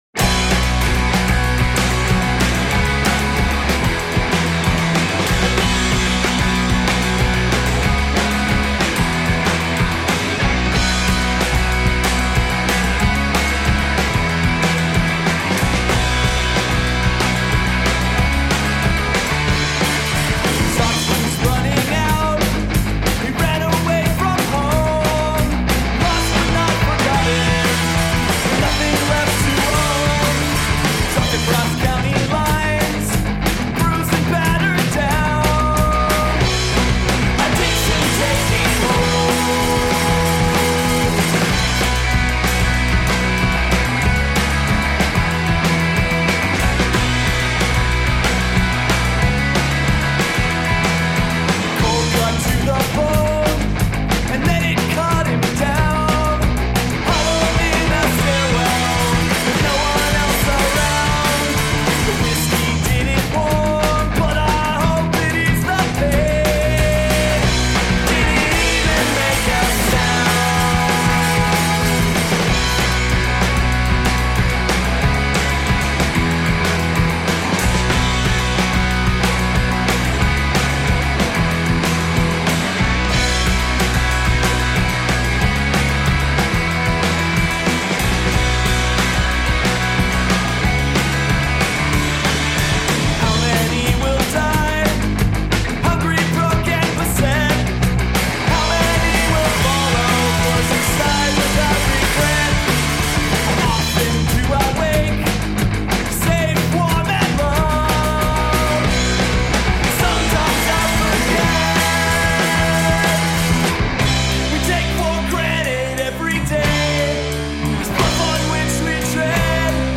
Three-piece band
political pop punk